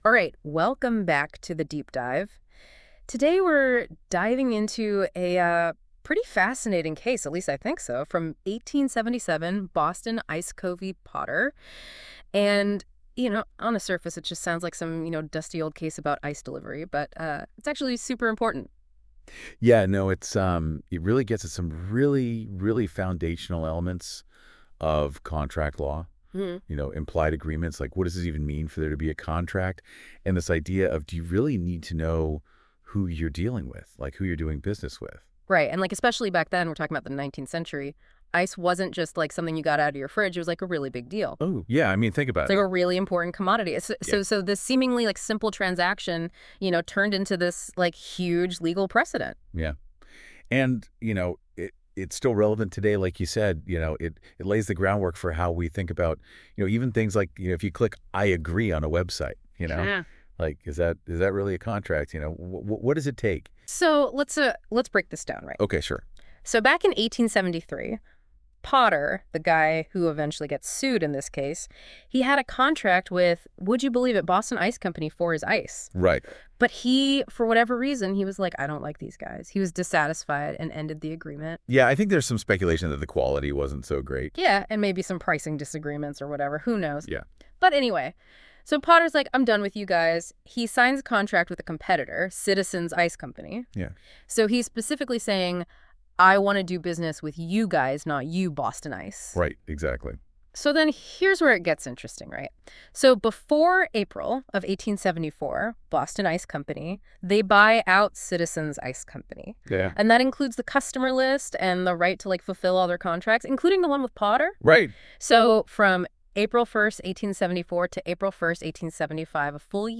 Listen to an audio breakdown of Boston Ice Co. v. Potter.